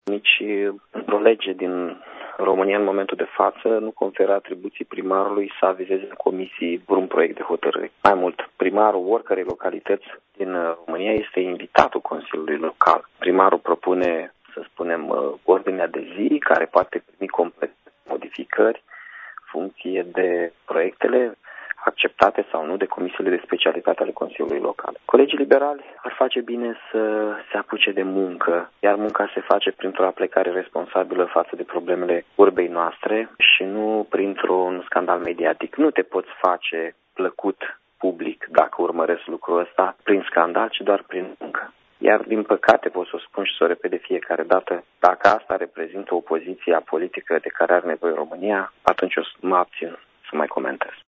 În replică, Mihai Chirica a declarat pentru Radio Iași că nu există nicio lege conform căreia primarul să fie obligat să avizeze în comisii un proiect de hotărâre: